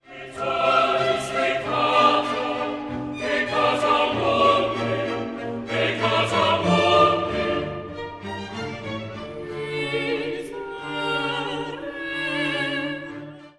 “K49” is scored for violins, violas, basses, organ continuo, and three trombones doubling alto, tenor, and bass vocal parts.
The Gloria is through-composed as a sort of jolly march, with the words sung alternately by the chorus and various soloists.
Always the orchestra maintains an insistent metronome-like beat, which makes us want to get up out of our seats and march around the church.
Qui tollis“, chorus (except for the first “Miserere” for soloists in various combinations); “Quoniam“, soprano; “Cum sancto spiritu“, Type II chorus.